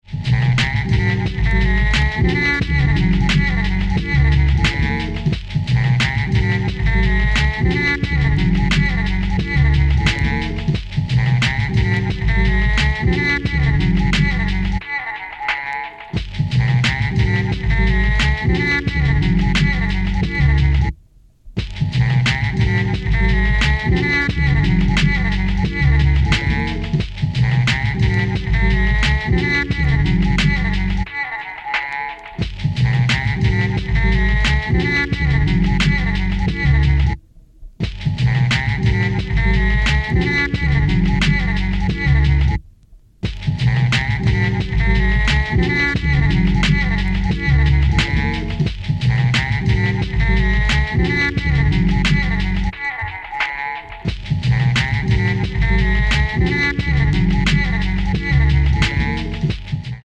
is a mini-album soley made of Romanian Jazz records